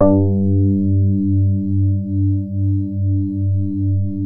JAZZ SOFT G1.wav